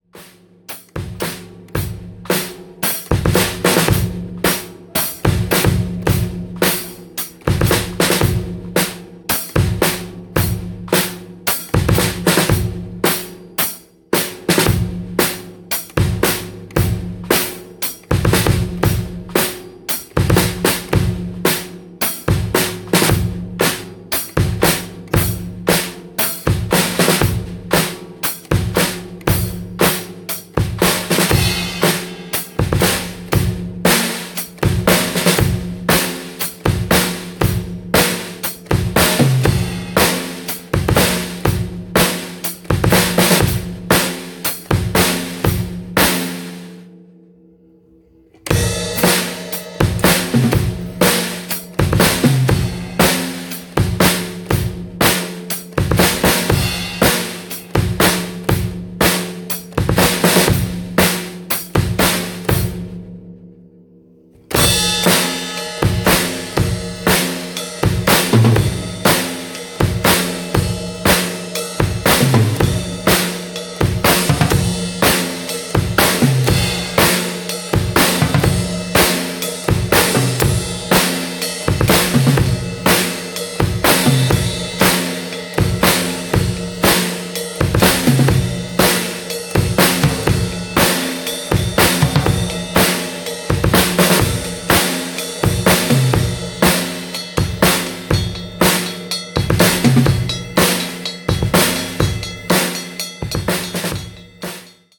Which is why, last week, after a gap of over five years, I picked up my drumsticks and went to a jam room in South Extension where there’s a nice drum set, and I practiced playing the drums for an hour.
I was rusty, stiff in bone and muscle and brain, I panted and gasped at the exertion, I missed a beat every nine beats on average.
I managed to record part of my solo cacophony, and place three short sections below – missed beats and all – for your torment, ferment and comment, O patient and long-suffering reader!
Don’t worry, I promise you I won’t post any more of my solo practice sessions.